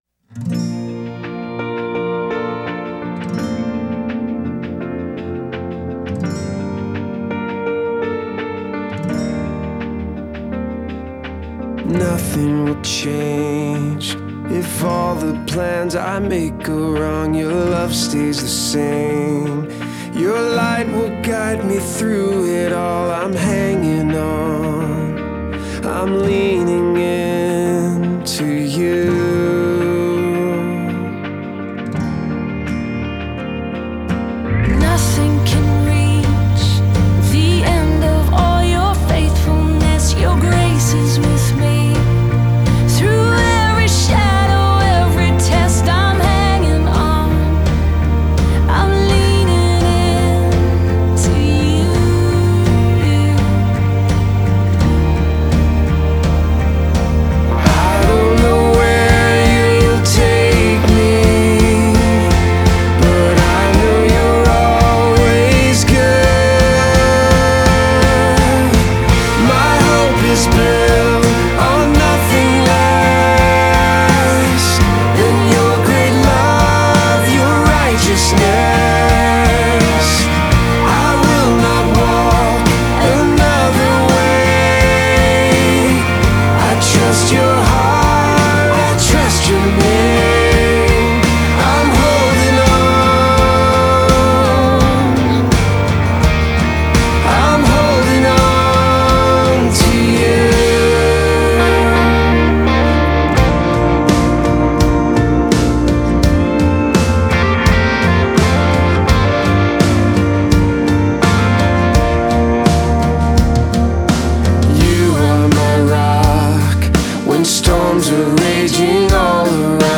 did it beautifully this morning for worship